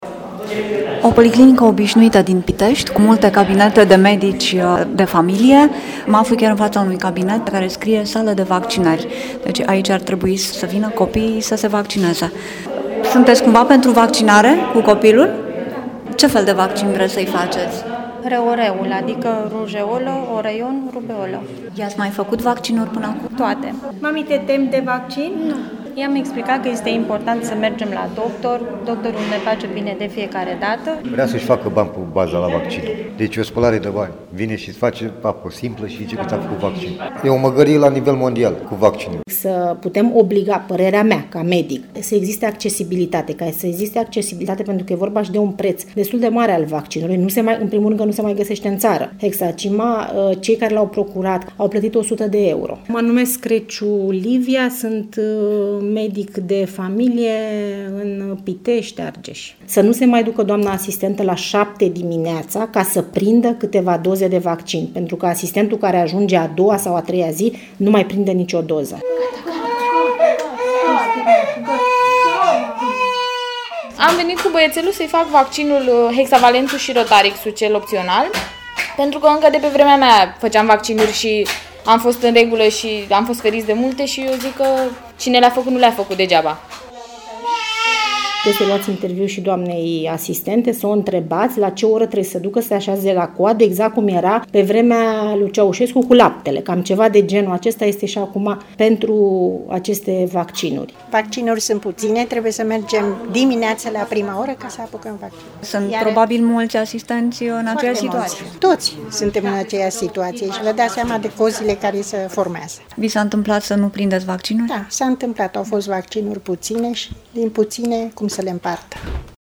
Un reportaj